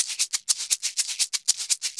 Maracas_ ST 120_1.wav